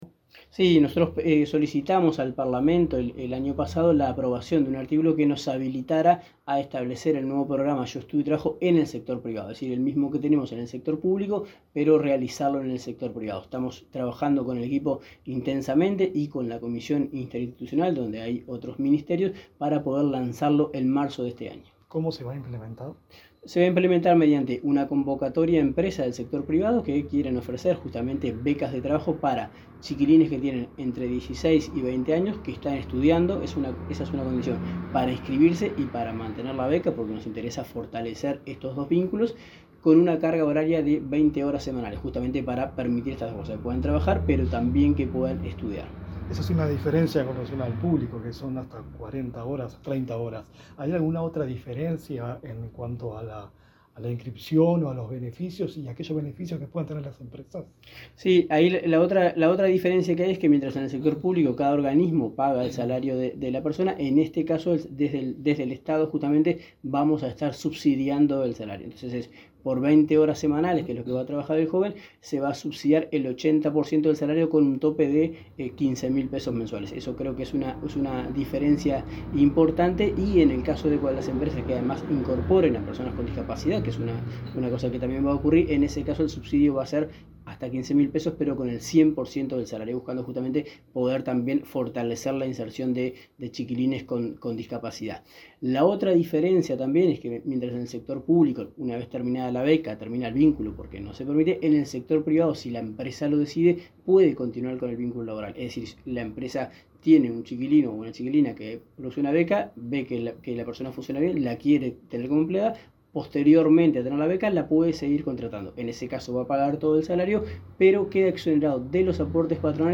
Entrevista al director nacional de Trabajo, Daniel Pérez